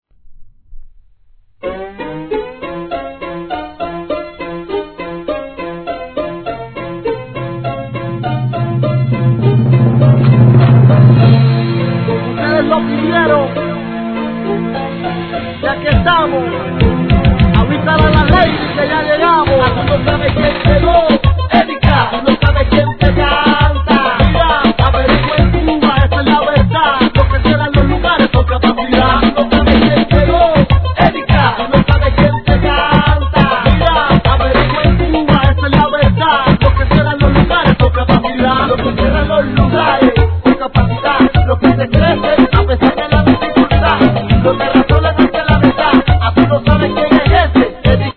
■REGGAETON